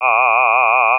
Vibrato
Vibrato is shown using Ahh, as in the a in Father. An Oscillator in CSound is used to produce the vibrato - ranging the pitch from a semitone above to a semitone below the note's pitch.
First three formant values: F1: 730 F2: 1100 F3: 2450 Play it Continue